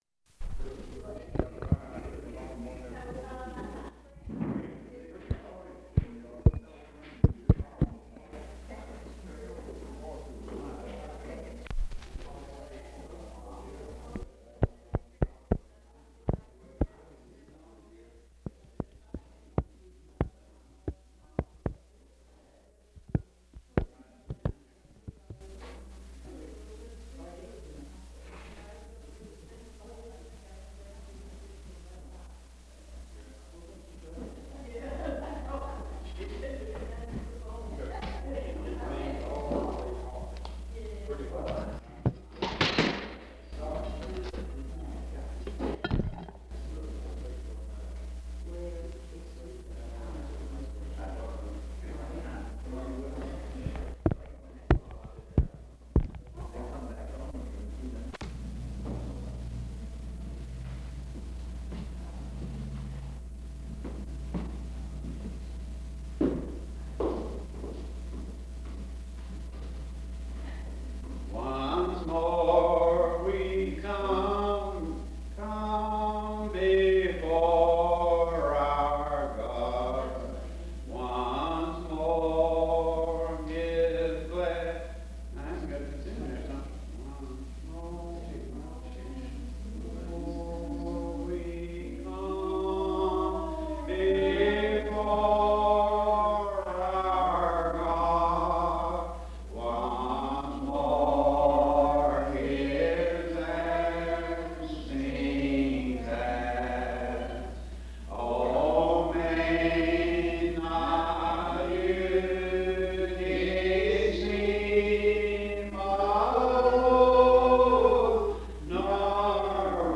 sermon collection